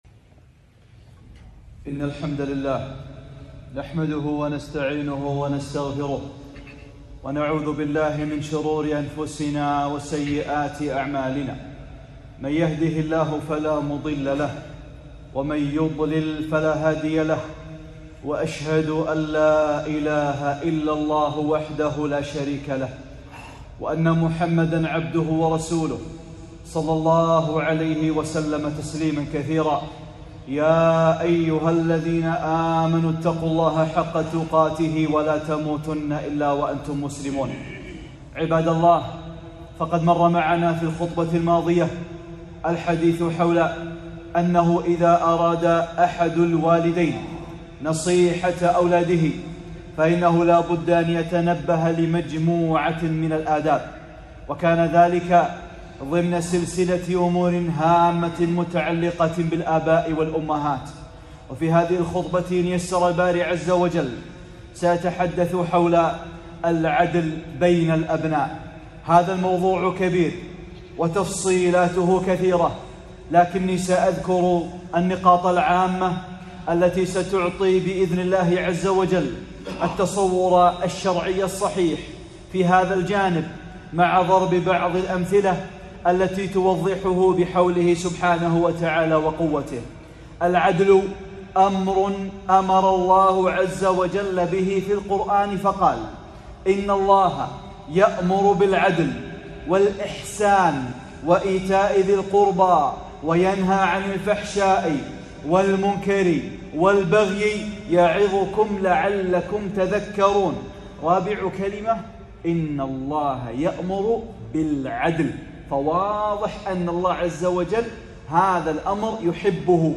(19) خطبة: العدل بين الأبناء - أمور هامة متعلقة بالآباء والأمهات